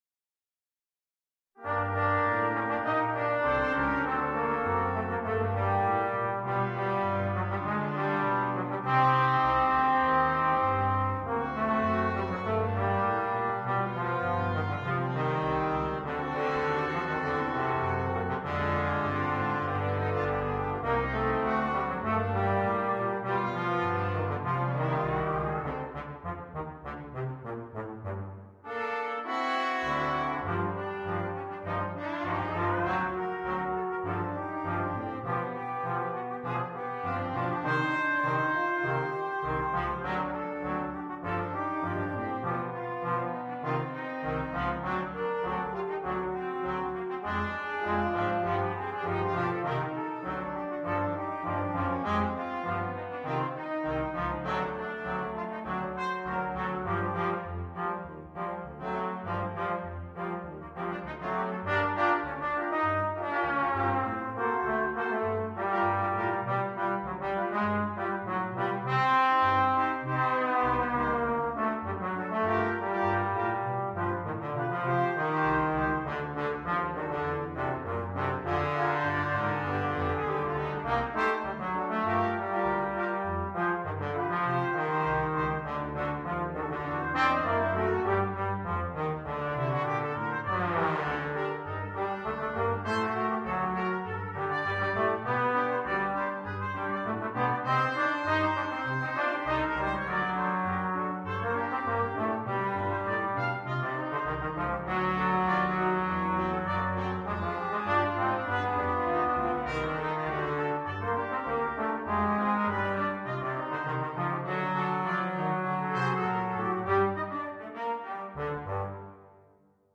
для брасс-квинтета.
• автор музыки: русская дворовая песня